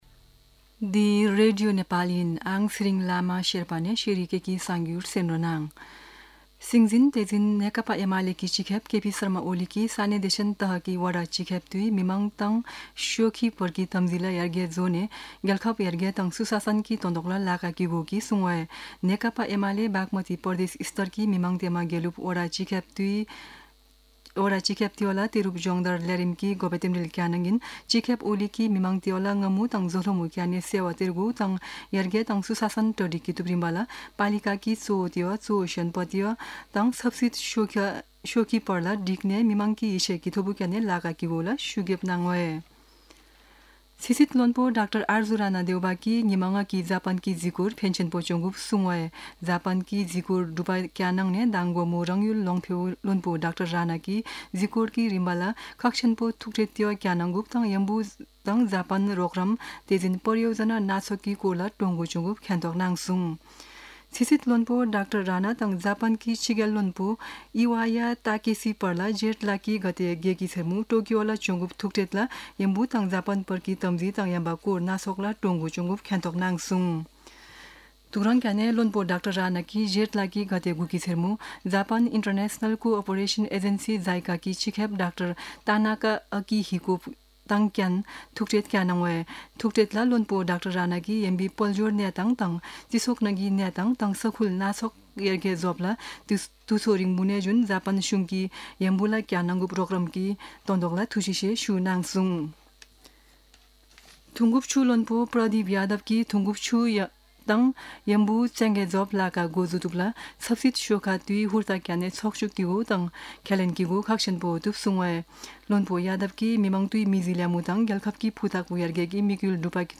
शेर्पा भाषाको समाचार : ११ जेठ , २०८२
Sherpa-News-11.mp3